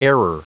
Prononciation du mot error en anglais (fichier audio)
Prononciation du mot : error